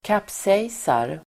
Ladda ner uttalet
kapsejsa verb, capsizeGrammatikkommentar: x/A &Uttal: [kaps'ej:sar] Böjningar: kapsejsade, kapsejsat, kapsejsa, kapsejsarSynonymer: haverera, kantraDefinition: slå runt med en båt, kantra